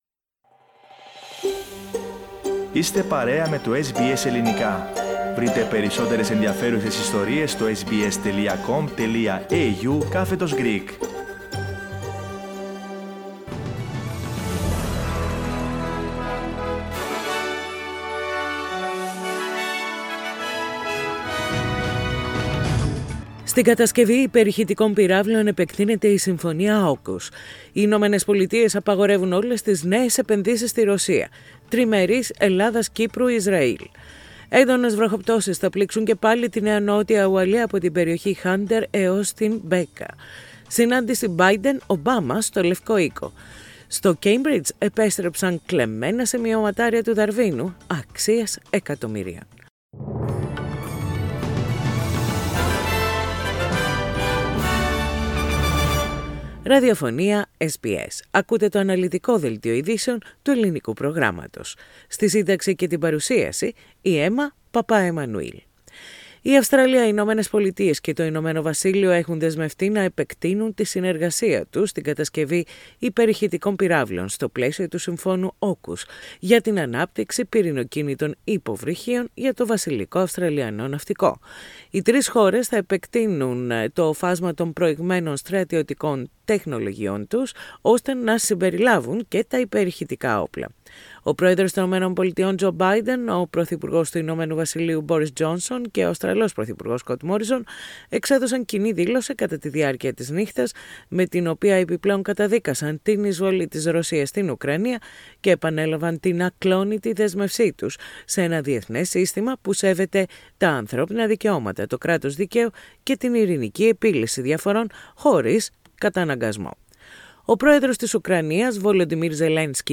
Δελτίο Ειδήσεων - Τετάρτη 6.4.22
News in Greek. Source: SBS Radio